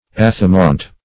Search Result for " athamaunt" : The Collaborative International Dictionary of English v.0.48: Athamaunt \Ath"a*maunt\, n. Adamant.